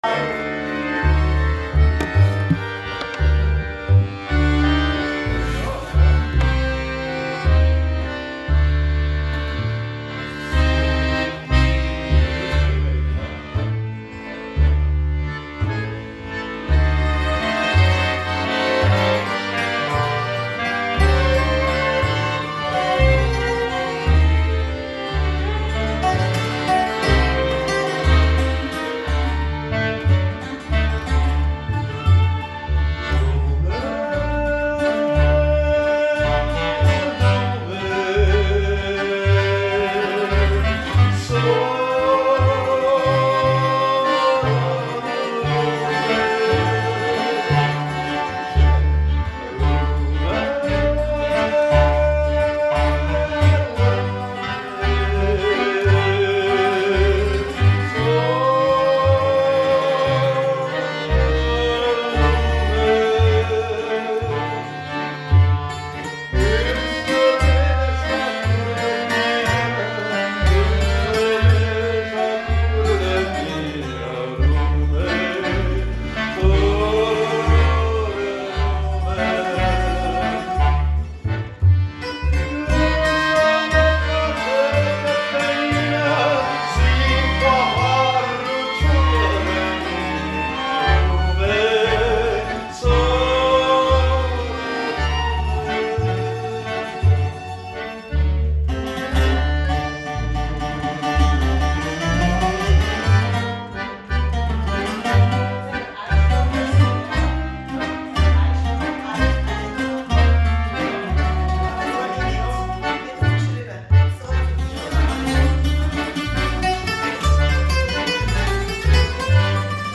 Geschmeidig und flink wie eine Katze spielen sie sich durch den Balkan und andere Weltgegenden.
Gitarre
Kontrabass, Perkussion
Bouzouki, Gesang
Akkordeon
Geige, Gesang
Gesang, Klarinette
Lume: Trad. Rumänien (Proben-Aufnahme)